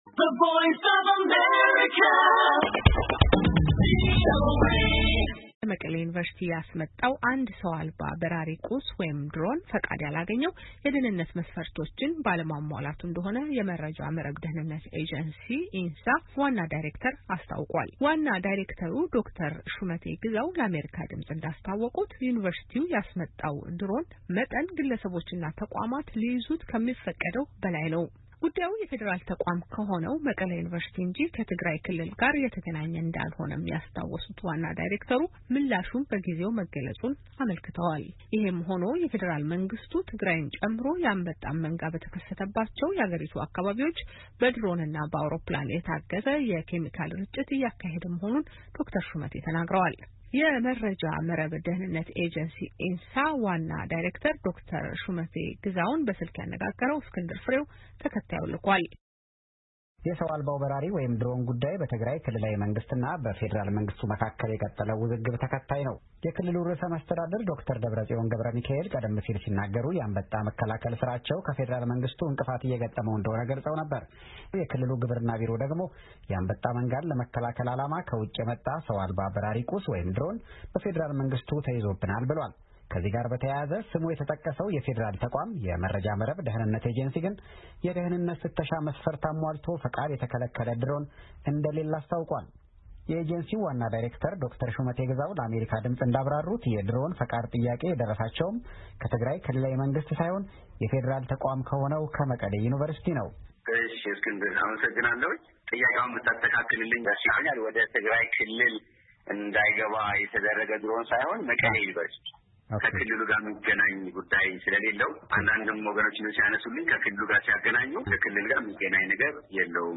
የመረጃ መረብ ደኅንነት ኤጀንሲ/ኢንሳ/ ዋና ዳይሬክተር ዶ/ር ሹመቴ ግዛውን በስልክ አነጋግረናል።